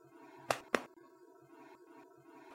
Tiếng Vỗ Vai…
Thể loại: Tiếng động
Description: Tiếng vỗ, đập, chạm, pat nhẹ lên vai vang “bộp… bốp” ấm áp hoặc dứt khoát, tùy theo ý định. Âm bàn tay tiếp xúc với lớp vải và cơ thể tạo ra tiếng trầm ngắn, kèm chút rung nhẹ lan qua vai.
tieng-vo-vai-www_tiengdong_com.mp3